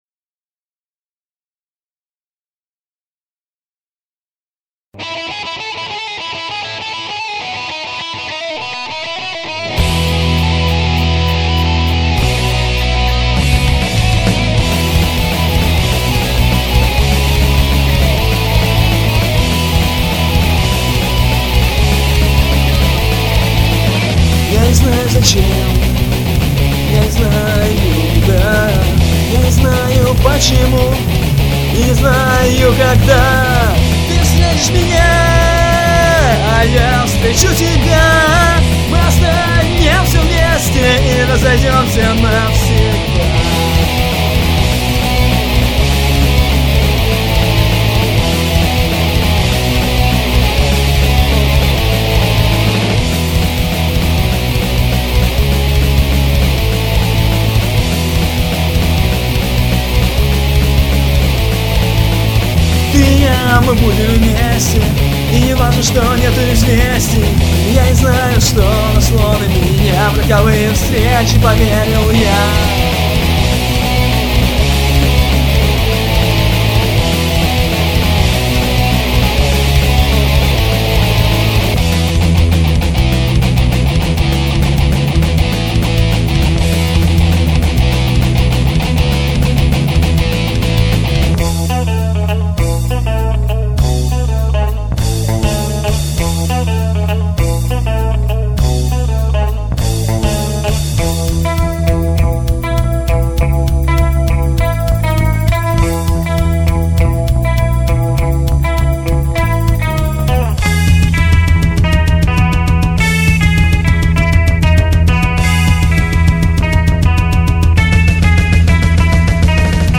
(demo)